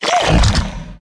naga_commander_attack_2.wav